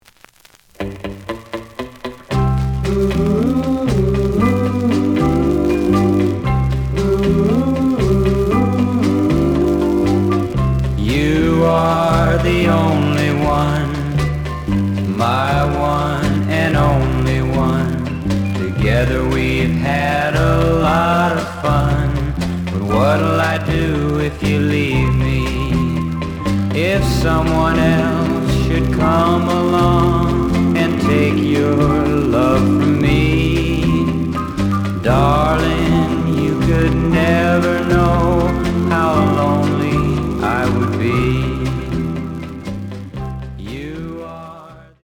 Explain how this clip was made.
The audio sample is recorded from the actual item. Some noise on A side.